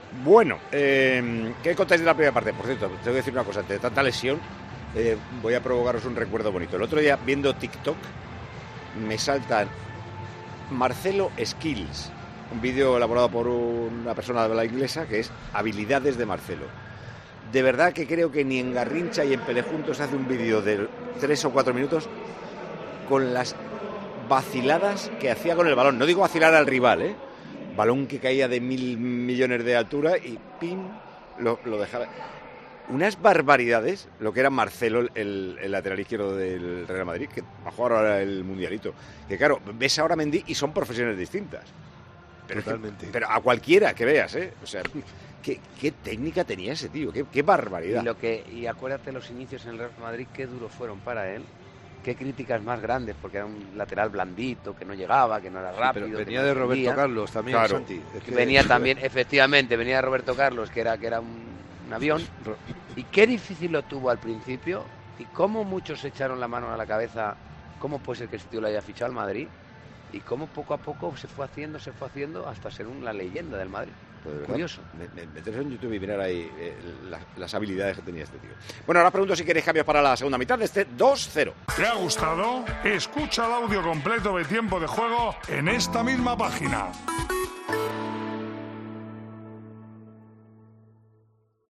El director y presentador de Tiempo de Juego recordó al descanso del Real Madrid-Villarreal un vídeo que había visto durante los últimos días de un exjugador del equipo blanco.
El exguardameta del Real Madrid, y actual comentarista en Tiempo de Juego, Santi Cañizares, también se unió a esa reflexión sobre el lateral brasileño y quiso recalcar la mejora que tuvo en el equipo blanco a lo largo de los años.